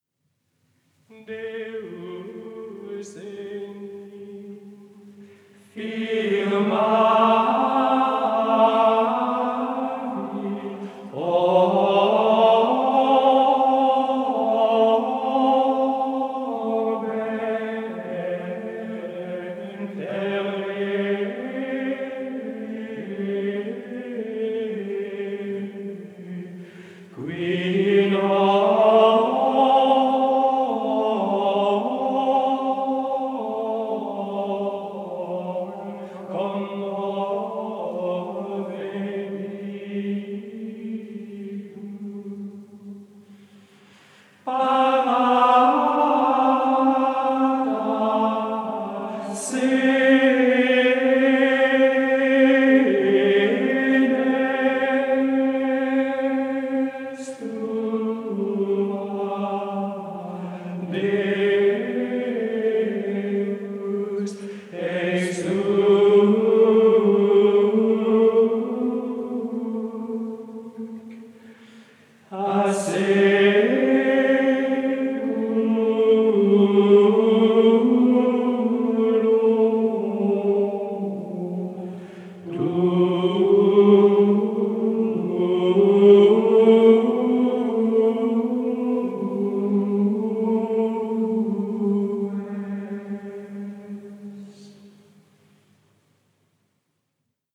• Les pièces grégoriennes du dimanche dans l'octave de Noël
Microsillon 45 T de Ligugé édité par le Studio SM en 1958